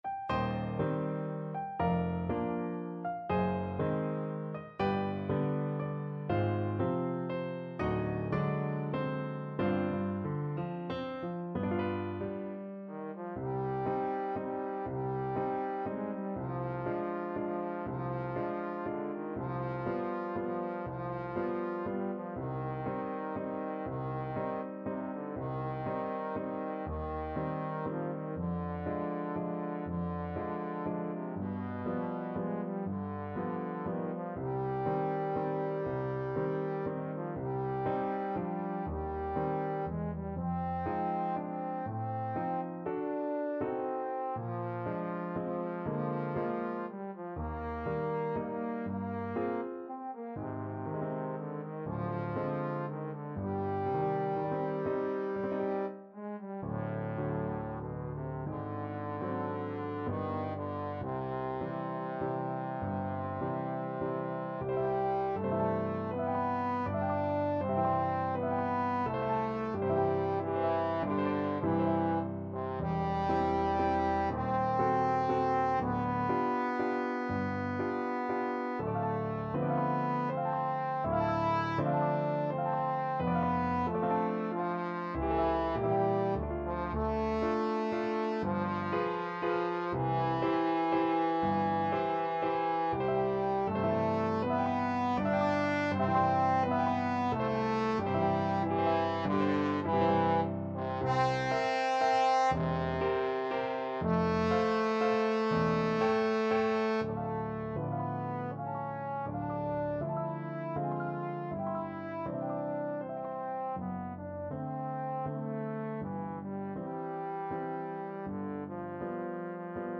Classical
3/4 (View more 3/4 Music)
~ = 120 Lento